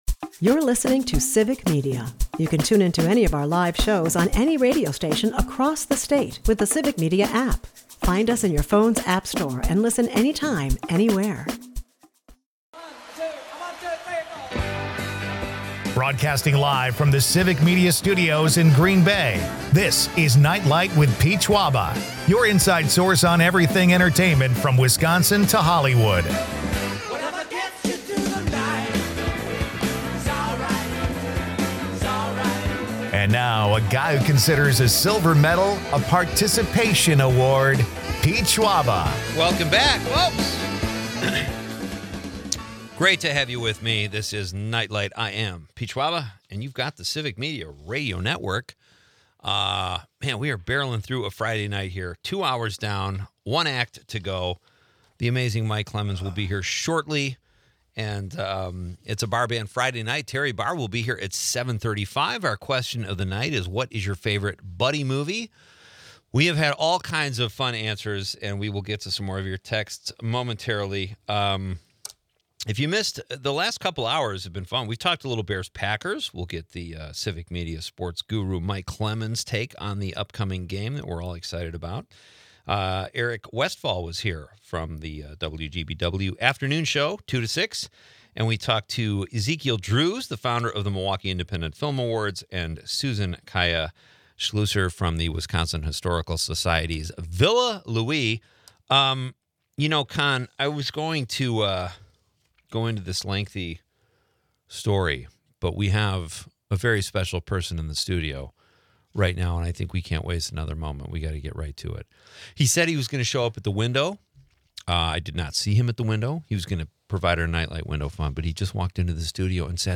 A perfect blend of sports fervor and musical flair.